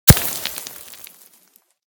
/ gamedata / sounds / material / bullet / collide / sand01gr.ogg 28 KiB (Stored with Git LFS) Raw History
sand01gr.ogg